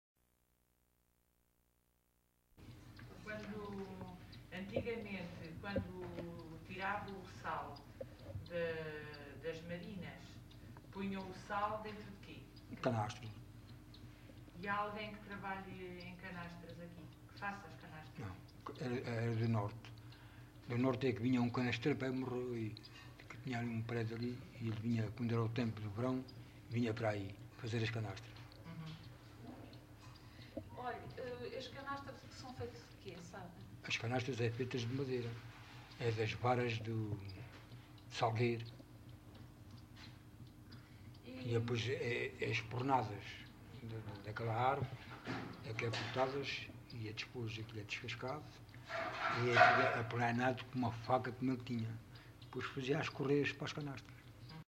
LocalidadeAlcochete (Alcochete, Setúbal)